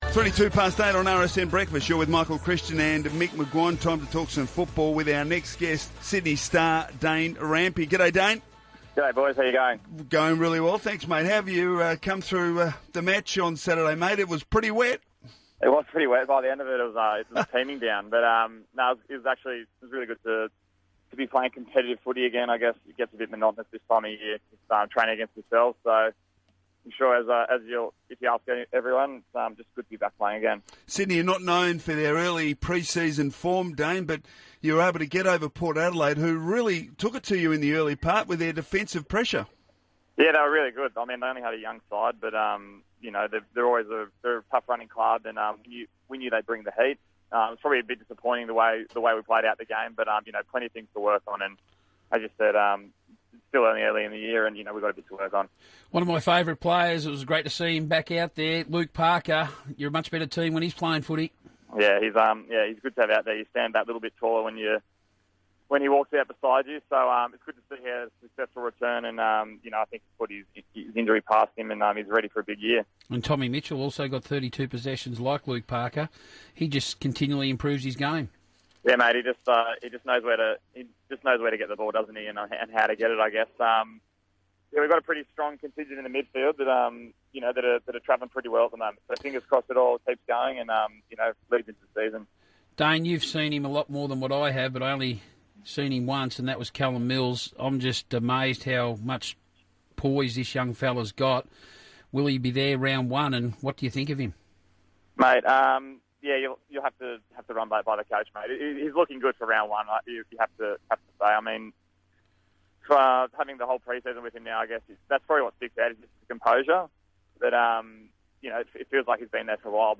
Dane Rampe on Radio Sport National
Dane Rampe speaks to Michael Christian and Mick McGuane about joining the leadership group.